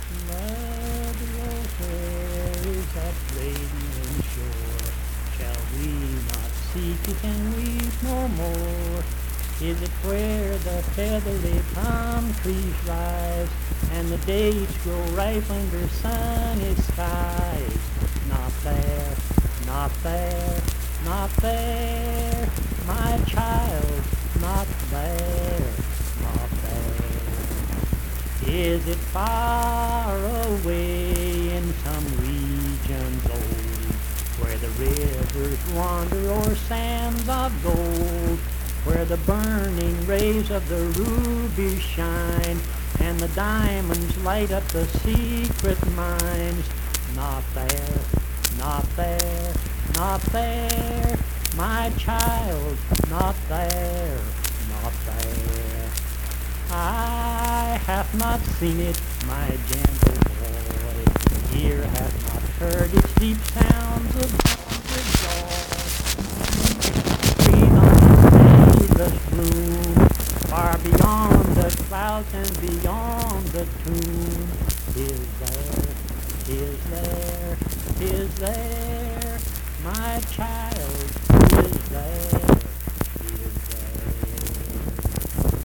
Accompanied (guitar) and unaccompanied vocal music
Verse-refrain 3(6w/R). Performed in Mount Harmony, Marion County, WV.
Hymns and Spiritual Music
Voice (sung)